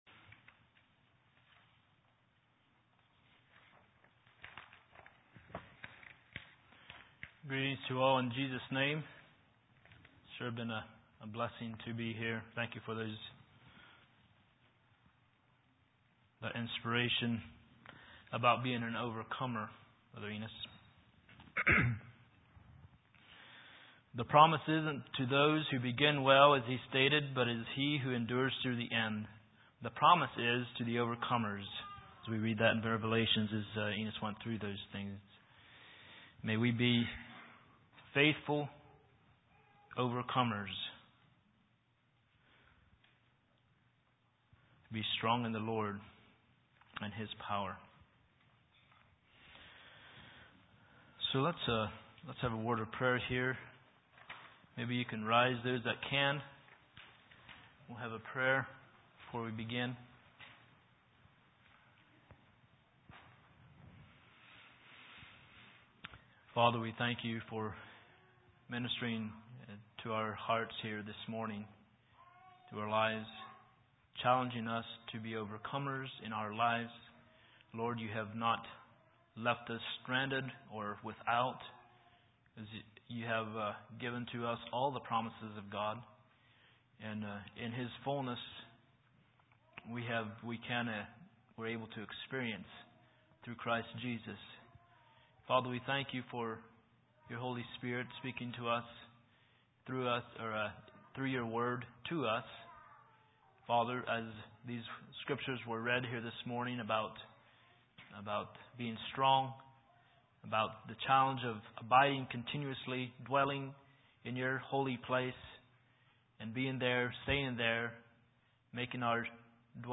2021 Sermons 9/14